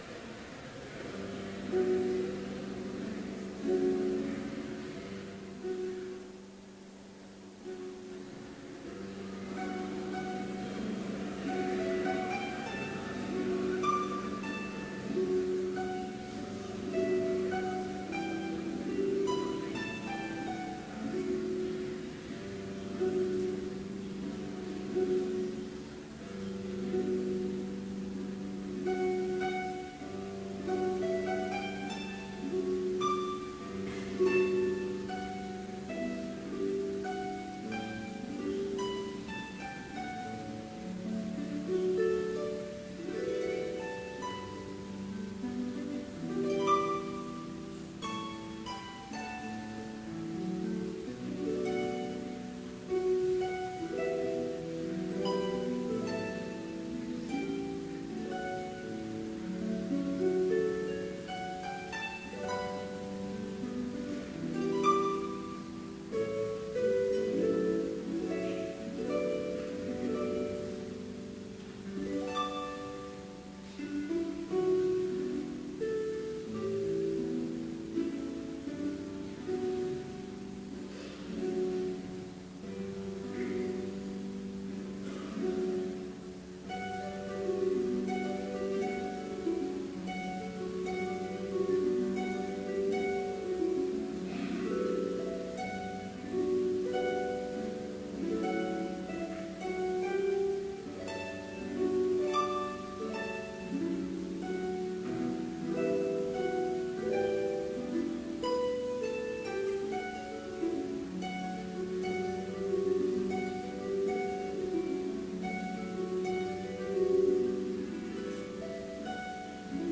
Complete service audio for Chapel - October 4, 2018